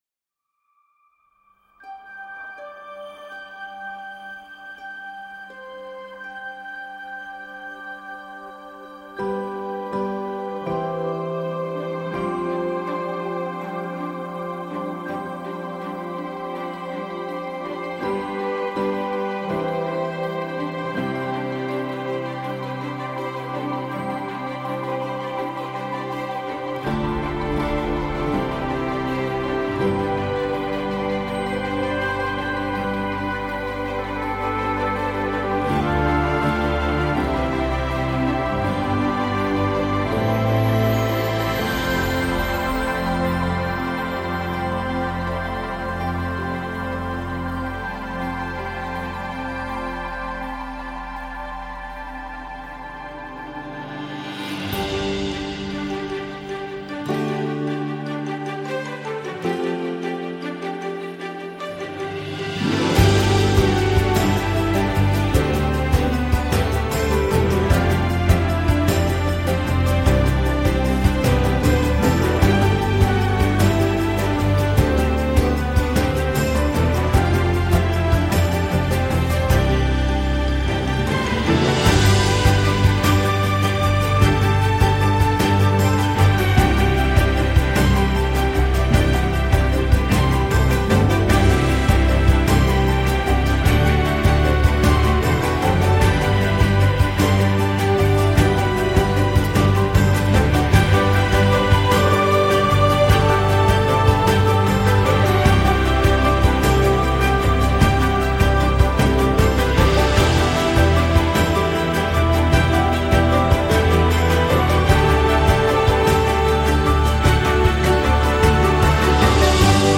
Joyeusement. Paisiblement.
Légère dans le sens aérienne et agréable.